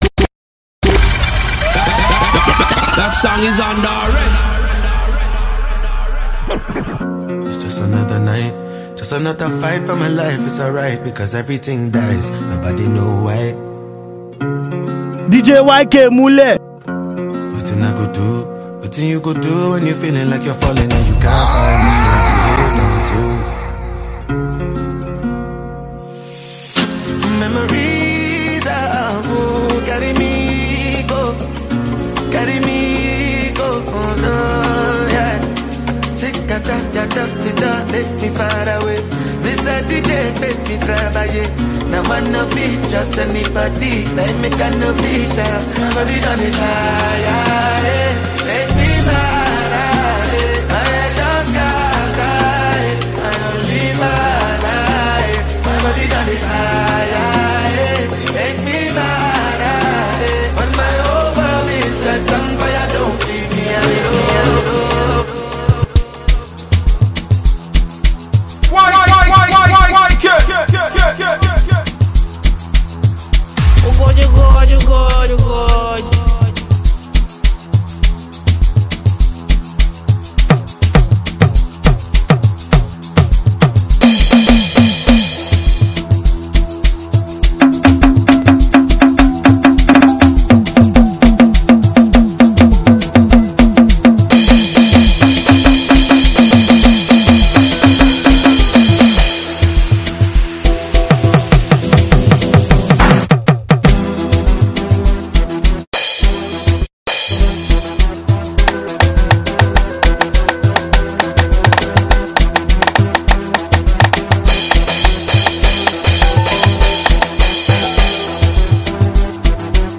He drops this banging mix for the Detty December.
and raving South African hits.